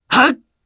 Fluss-Vox_Attack3_jp.wav